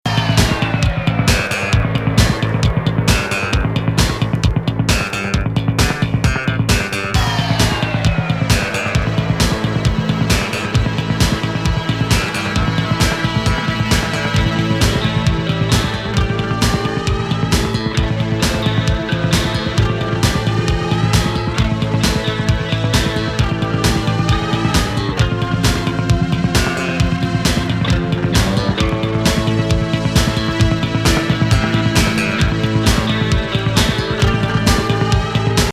ギターリフから強烈なシンセ・ロッキン・ディスコなドラムのダンスMIX。